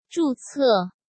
zhù cè